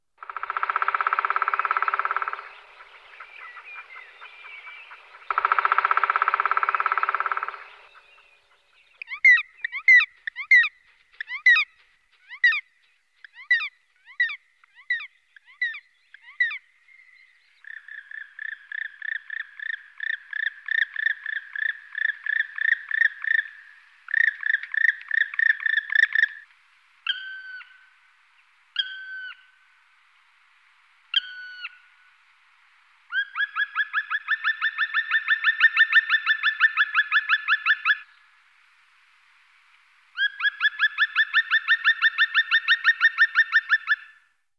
woodpecker-sound